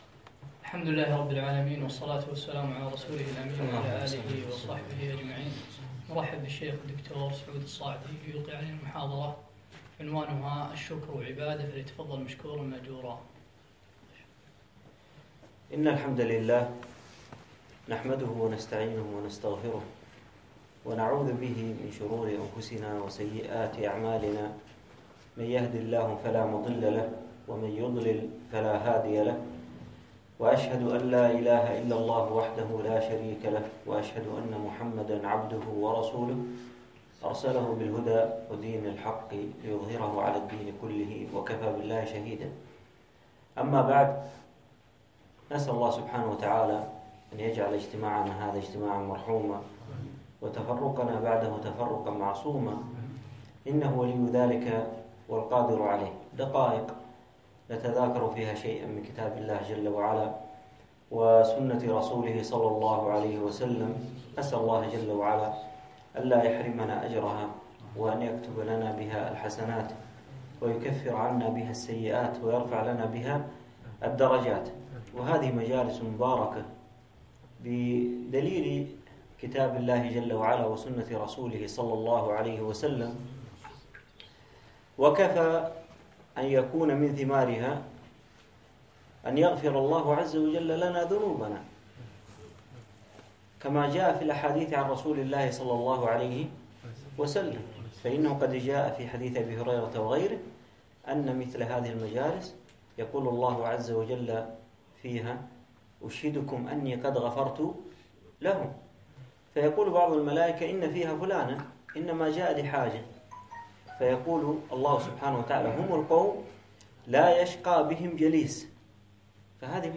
يوم السبت 7 جمادى أول 1438 الموافق 4 2 2017 في مسجد زين العابدين سعد ال عبدالله